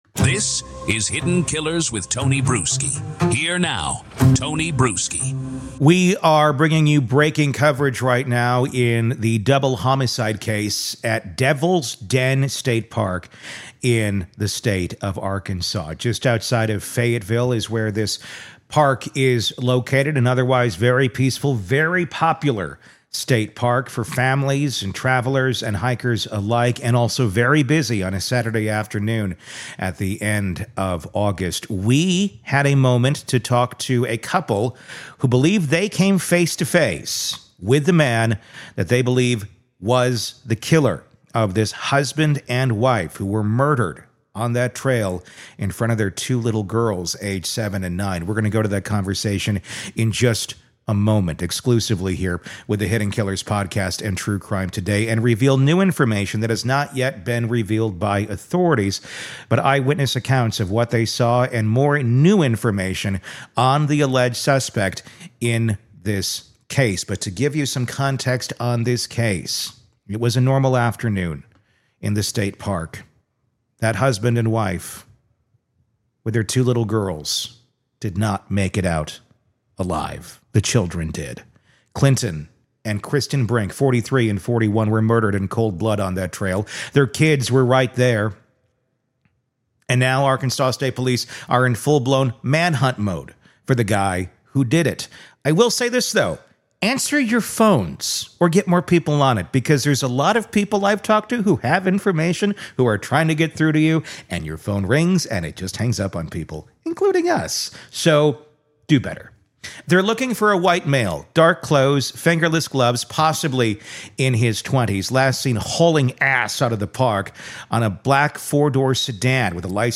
EXCLUSIVE INTERVIEW With Couple Who Came Face to Face With Devil’s Den Double Homicide Suspect!